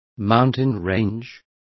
Also find out how sierra is pronounced correctly.